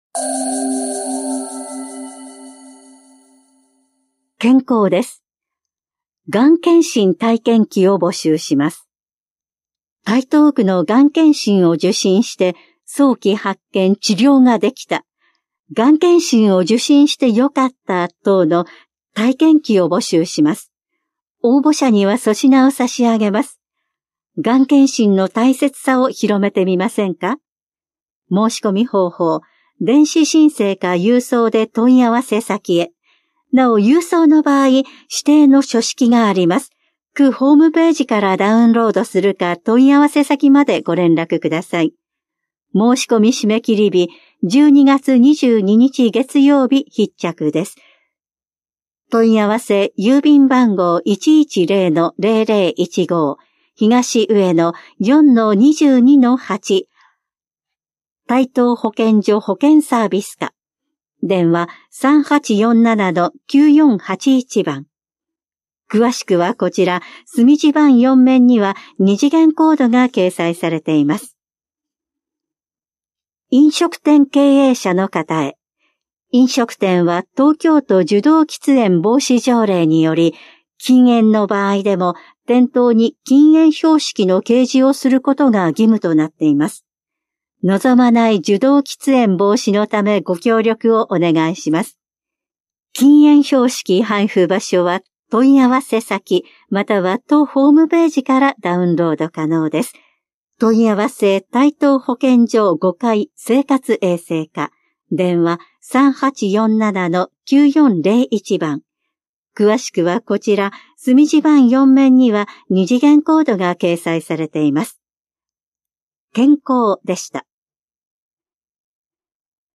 広報「たいとう」令和7年8月20日号の音声読み上げデータです。